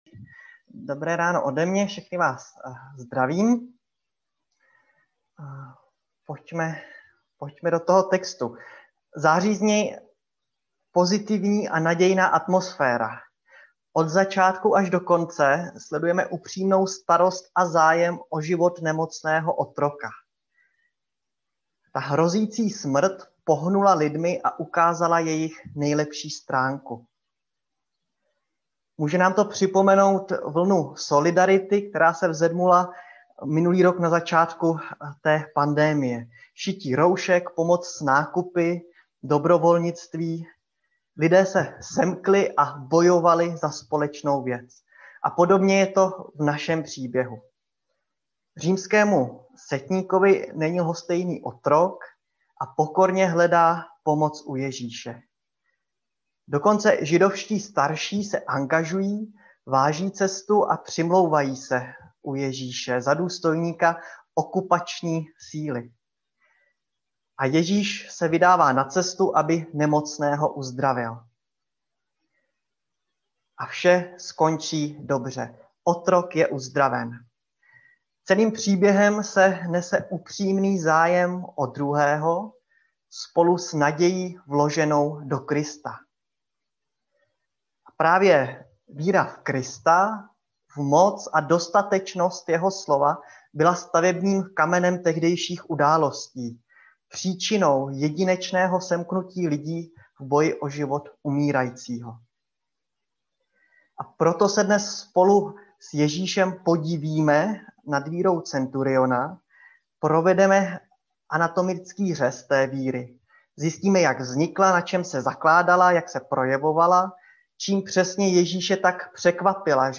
Nedělní kázání 7.3.2021